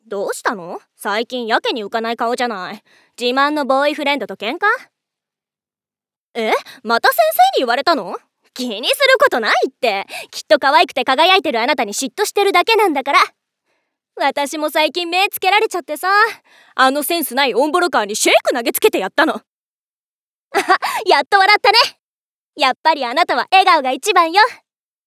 ボイスサンプル5.mp3